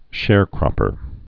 (shârkrŏpər)